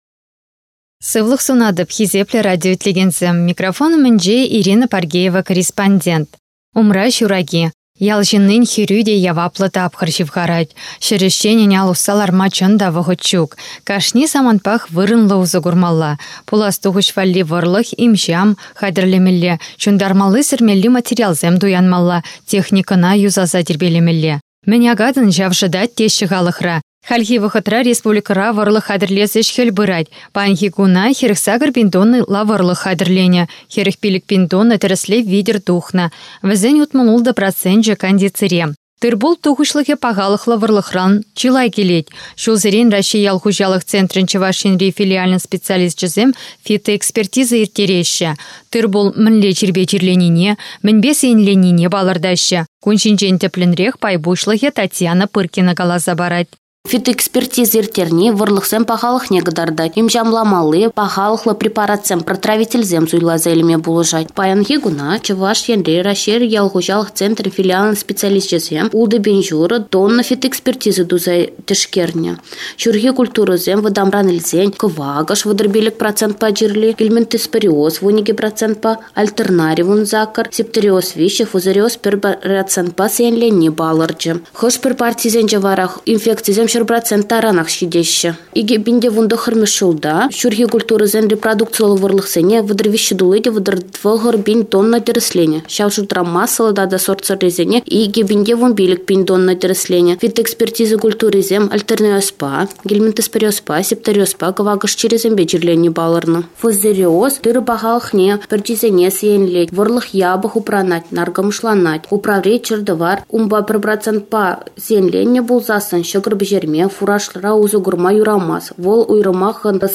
Первоисточник: ГТРК "Чувашия" - Радио Чувашии